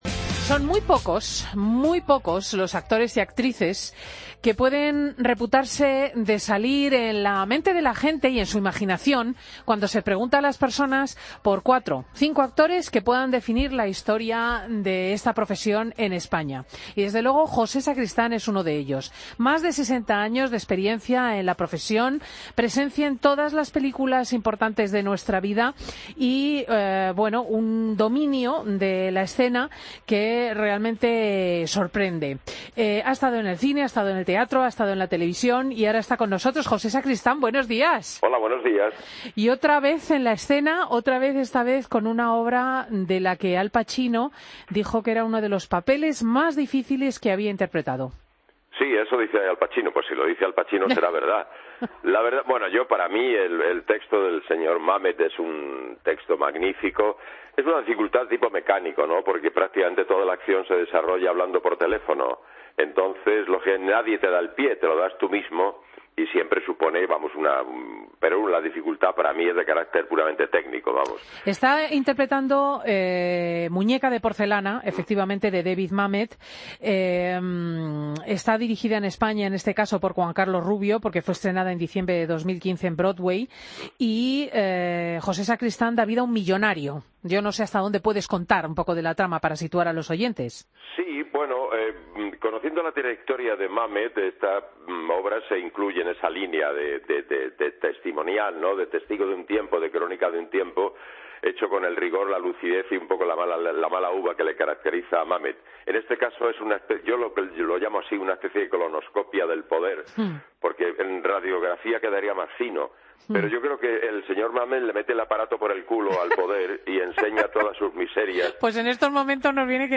AUDIO: Escucha la entrevista al actor José Sacristán en Fin de Semana COPE